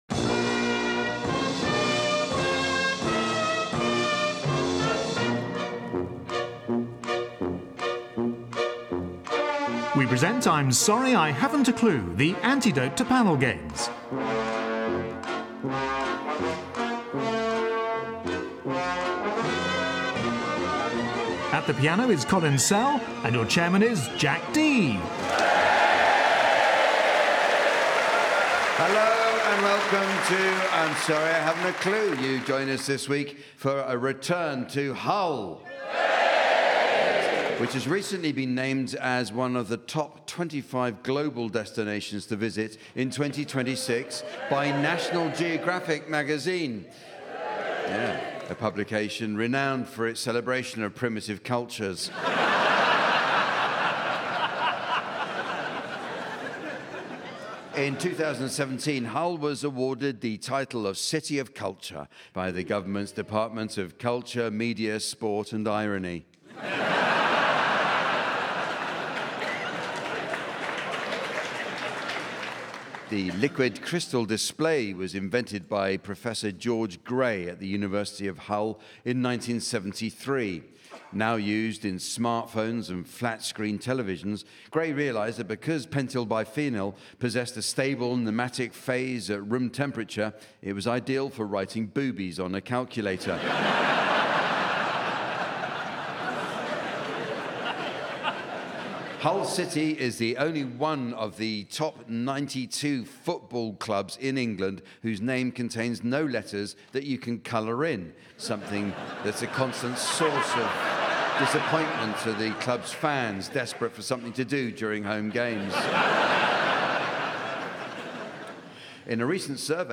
The godfather of all panel shows returns to the Hull New Theatre.
On the panel are Rory Bremner, Tony Hawks, Lucy Porter and Henning Wehn with Jack Dee in the umpire's chair. Regular listeners will know to expect inspired nonsense, pointless revelry and Colin Sell at the piano.